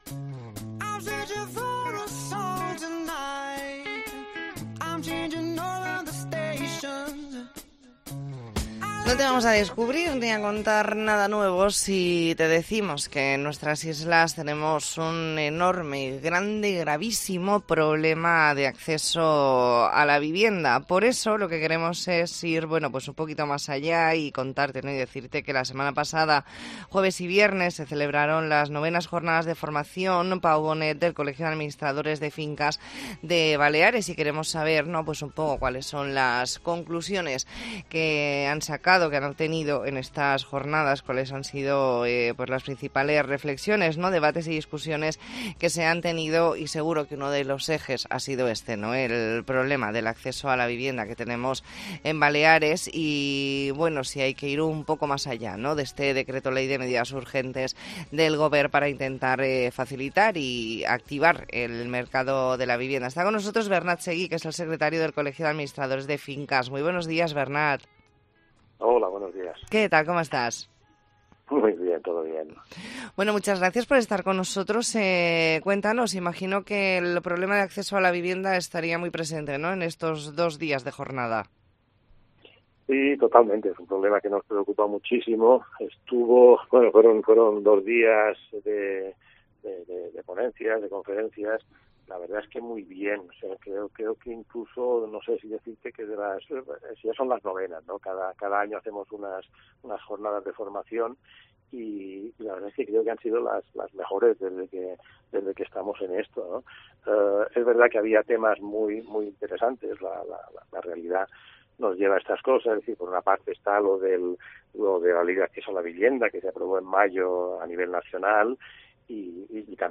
Entrevista en La Mañana en COPE Más Mallorca, lunes 13 de noviembre de 2023.